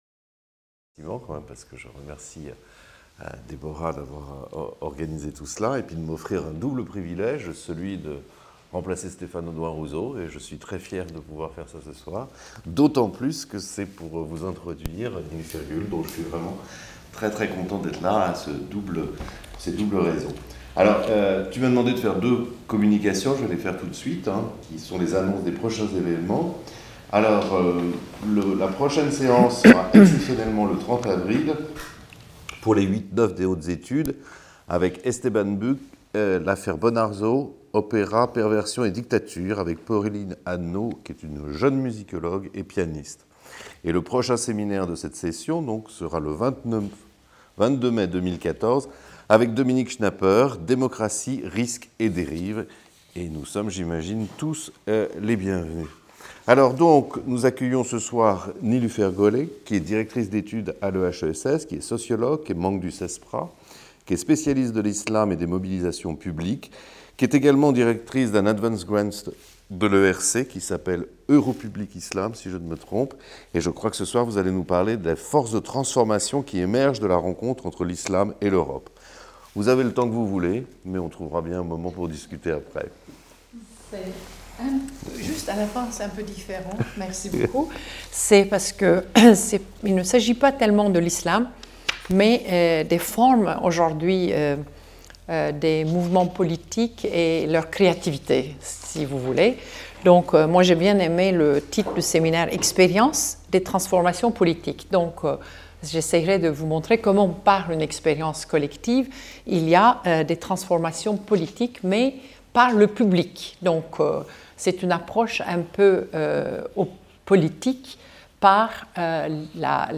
Séminaire dispensé par Nilüfer Göle Organisé en collaboration avec le labex Tepsis et le concours de l'Institut CDC pour la Recherche